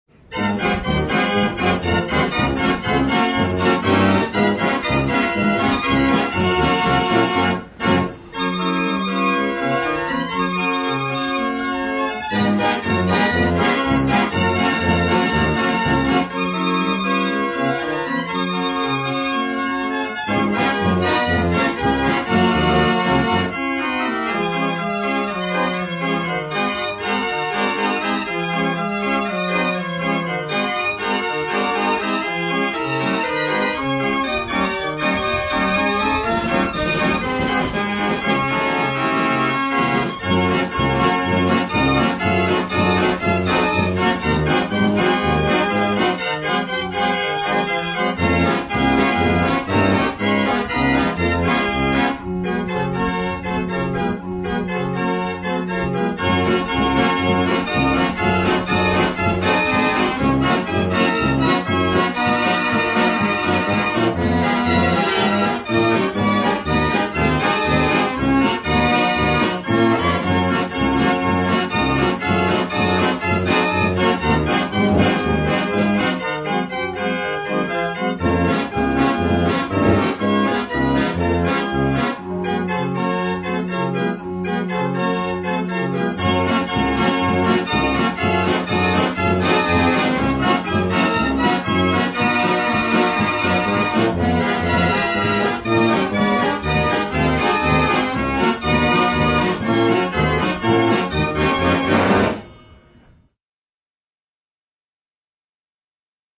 Unknown American Songs on 67-keyless Book Music
fair organ
The holes of the 67-keyless system are spaced exactly 6 holes per inch, just like Aeolian Pianola rolls.